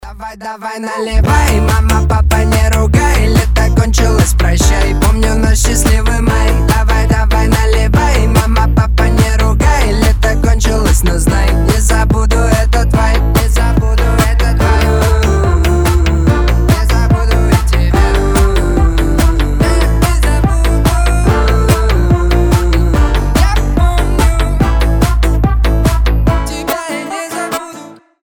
• Качество: 320, Stereo
веселые
смешные